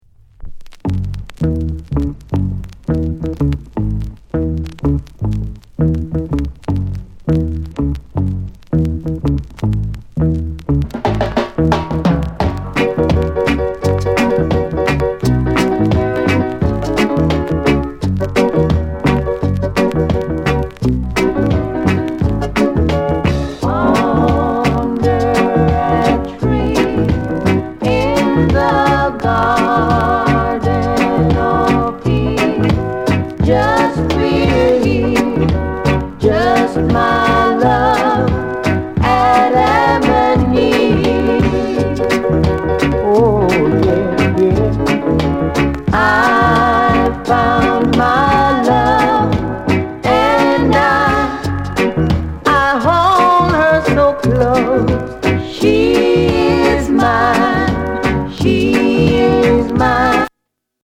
SOUND CONDITION A SIDE VG(OK)
NICE VOCAL